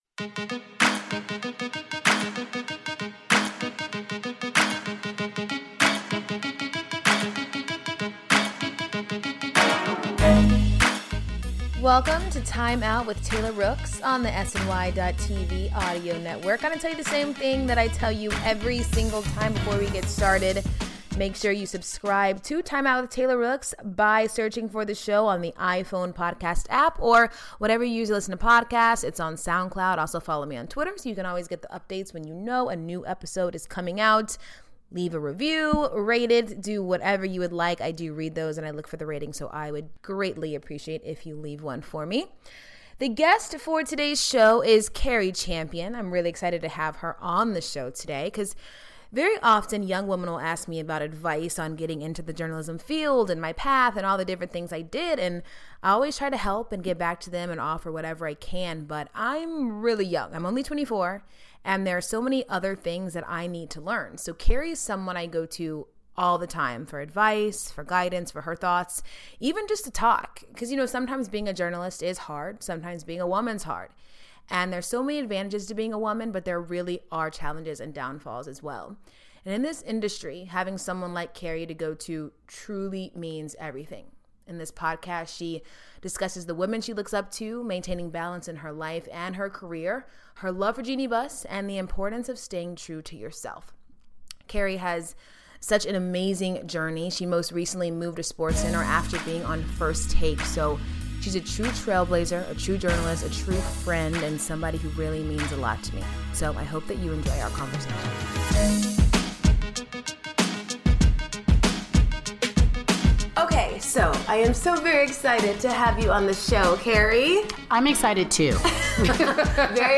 In the latest episode of Timeout with Taylor Rooks, ESPN's Cari Champion sits down with Taylor in Los Angeles to discuss the women she looks up to, maintaining balance in her life, and her love of Lakers President Jeanie Buss.